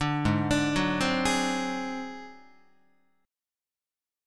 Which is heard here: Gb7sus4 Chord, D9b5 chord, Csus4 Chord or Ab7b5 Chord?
Ab7b5 Chord